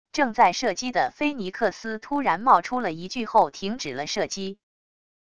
正在射击的菲尼克斯突然冒出了一句后停止了射击wav音频